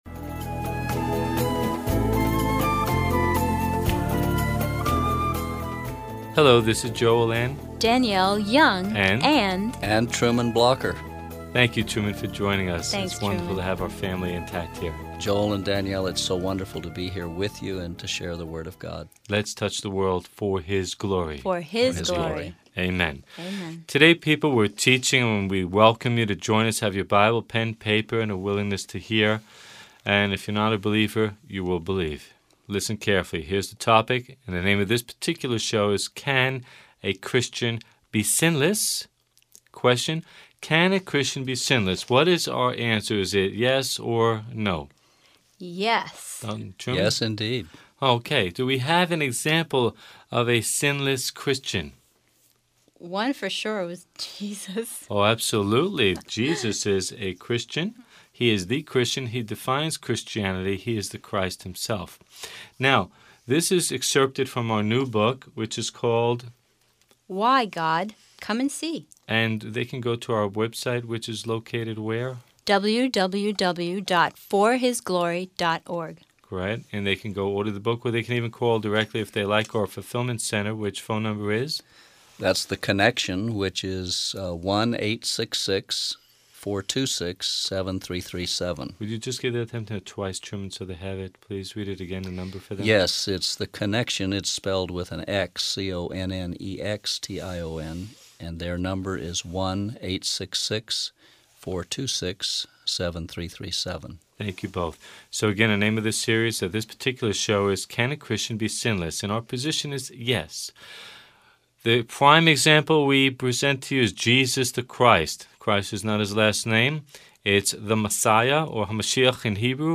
Radio Broadcasts